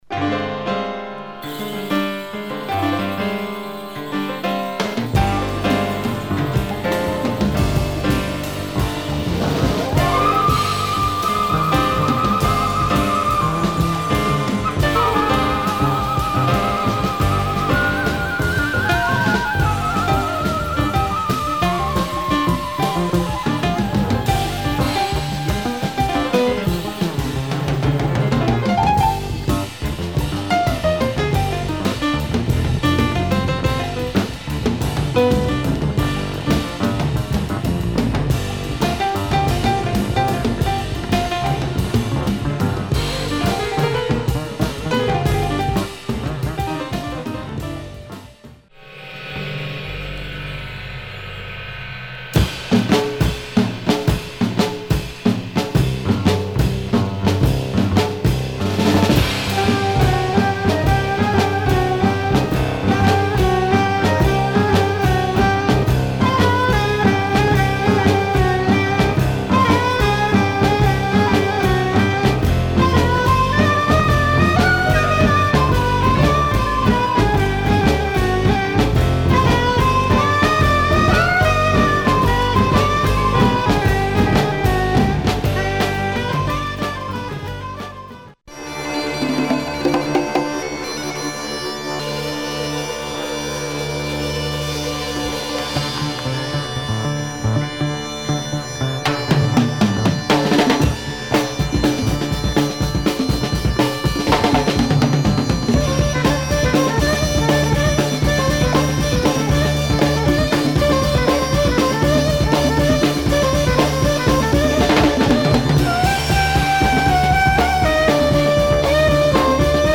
Top exotic jazz groove !